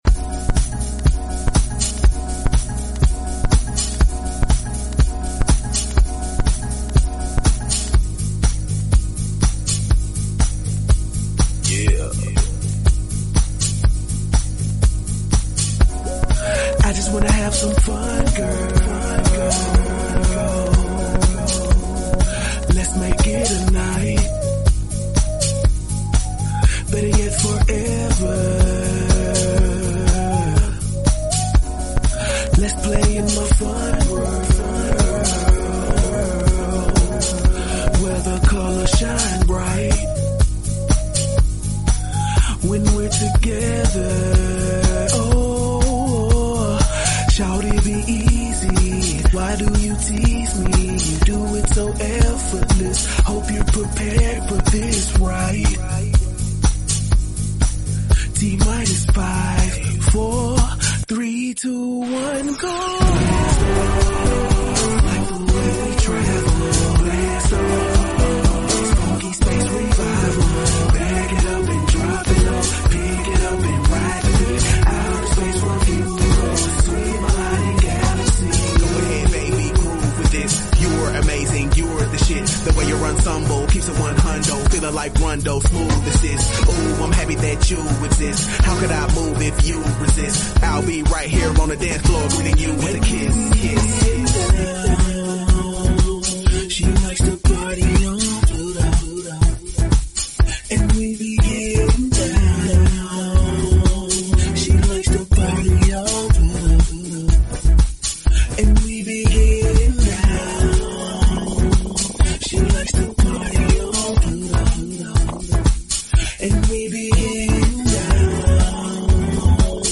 Talk Show Episode, Audio Podcast, Todays_Entrepreneurs and Courtesy of BBS Radio on , show guests , about , categorized as
TE Radio gives business owners a platform to discuss the ins and outs of the services they offer. During each broadcast, there will be in-depth guest interviews discussing the problems and advantages business owners face.
In addition to daily guests, TE spins the hottest indie musical artists and laughs along to the funniest comedians every day.